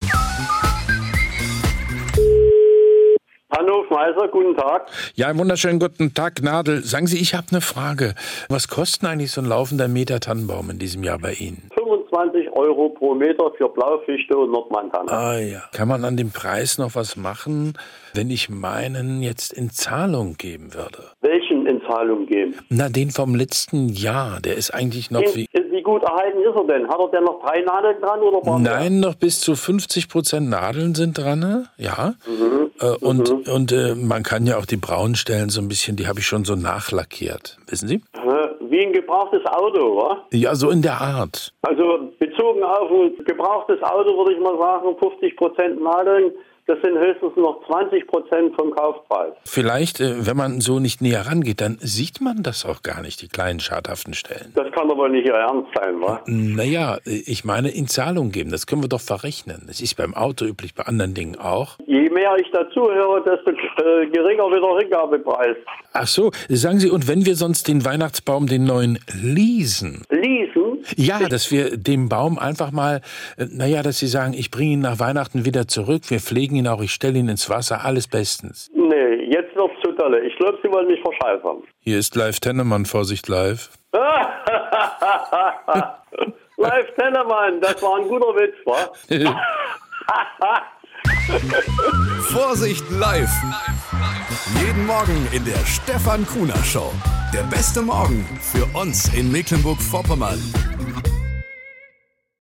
Nachrichten aus Mecklenburg-Vorpommern - 13.06.2024